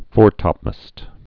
(fôrtŏpməst, -təp-măst)